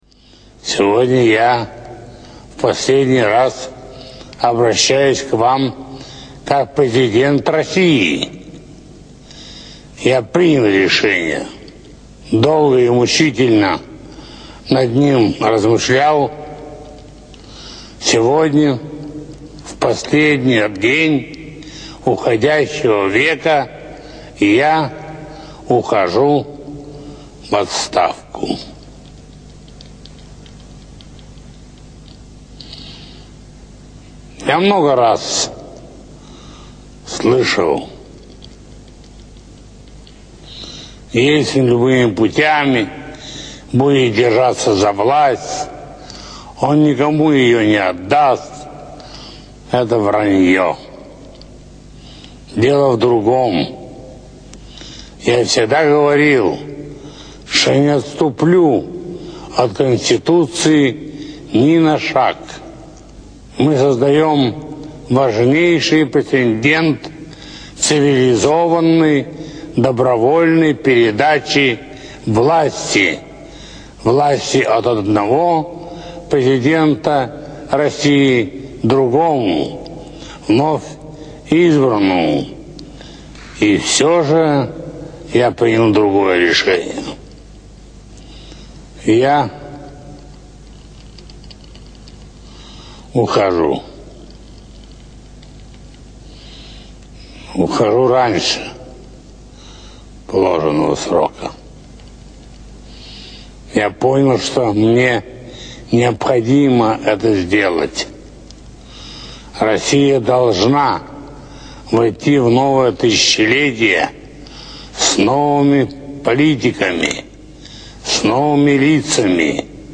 Boris Yeltsin TV address 1